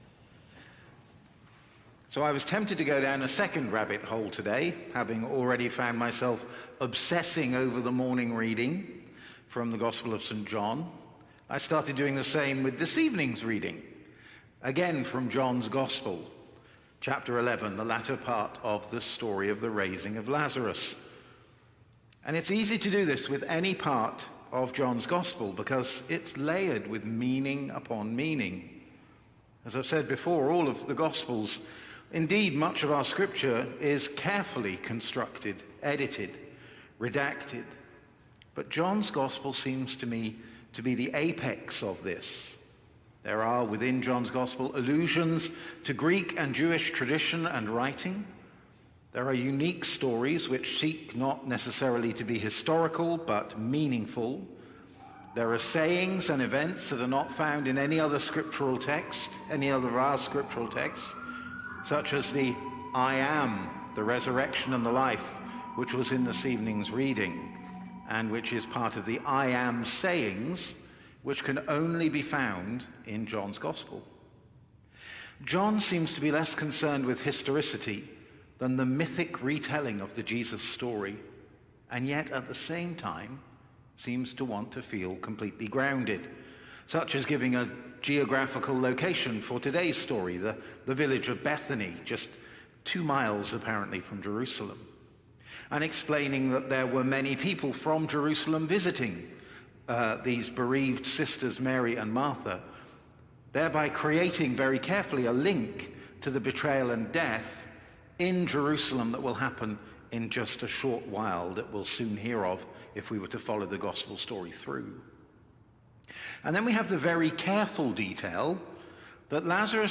Sermons | St. John the Divine Anglican Church
Evensong Reflection